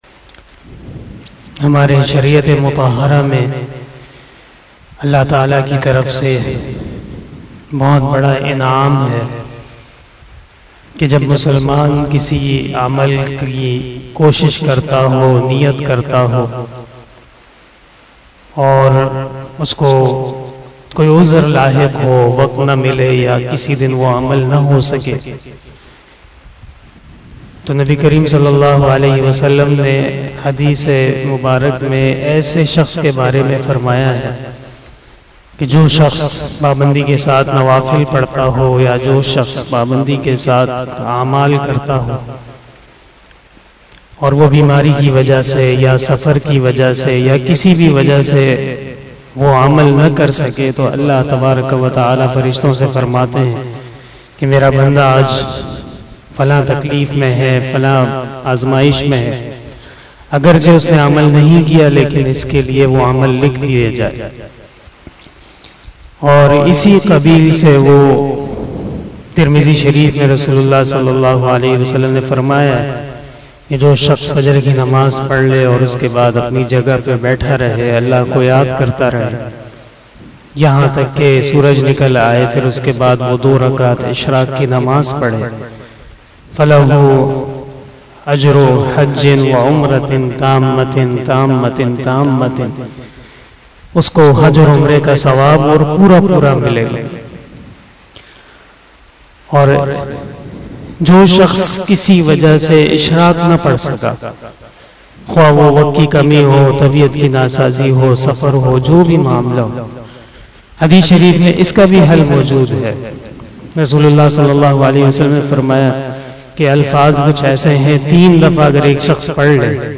After Fajar Namaz Bayan